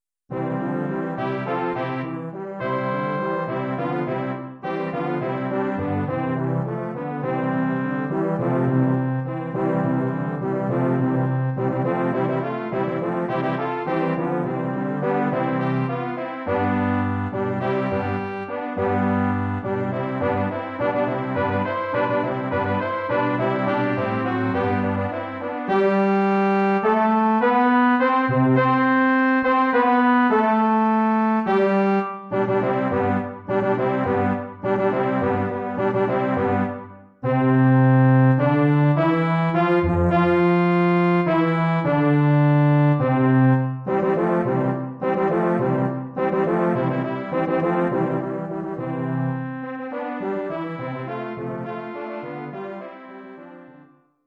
Arrangement / Hornensemble
Bearbeitung für 6 Hörner
Besetzung: 6 Hörner
arrangement for 6 horns
Instrumentation: 6 French horns